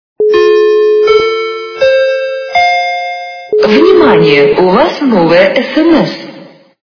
- звуки для СМС
» Звуки » звуки для СМС » Звонок для СМС - Гудки как на ж/д вокзале и фраза Внимание, у вас новое СМС
При прослушивании Звонок для СМС - Гудки как на ж/д вокзале и фраза Внимание, у вас новое СМС качество понижено и присутствуют гудки.